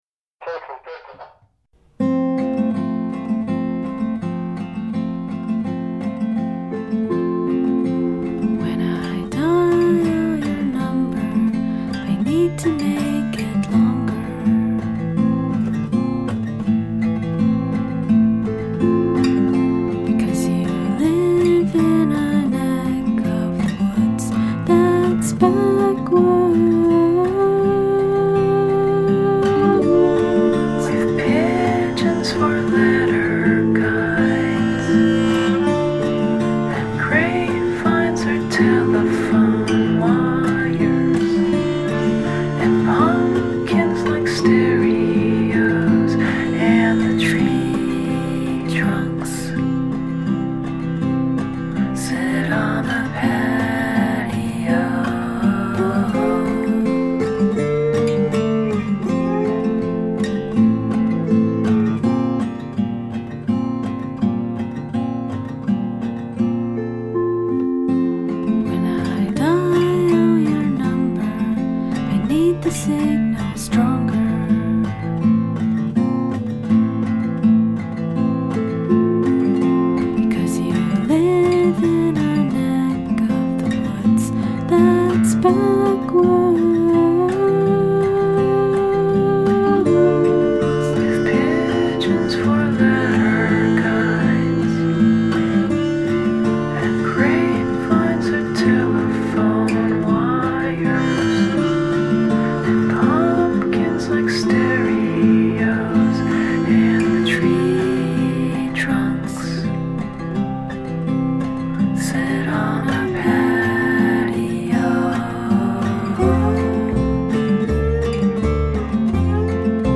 quirky pop-folk songs
gentle, pensive whisperings